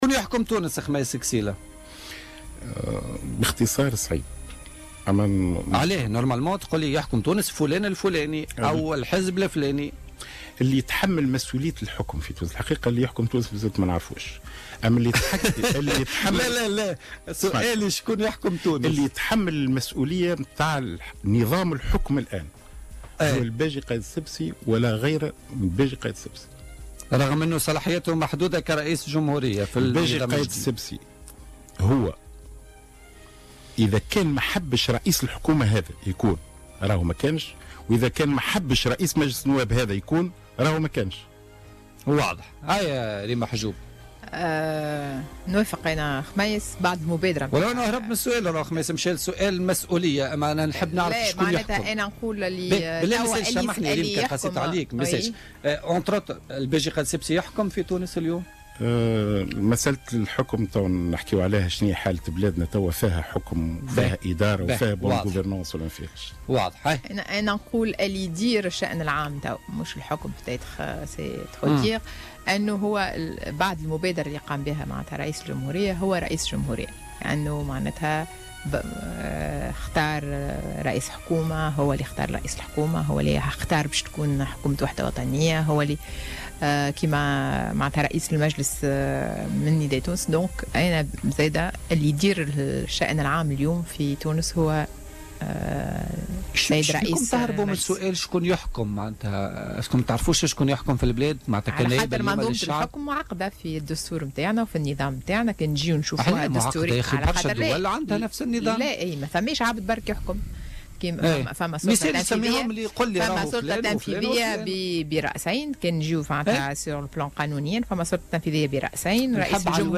اعتبر ضيف "بوليتيكا"، خميس قسيلة، النائب عن نداء تونس، أن حكومة يوسف الشاهد الحالية ليست حكومة وحدة وطنية.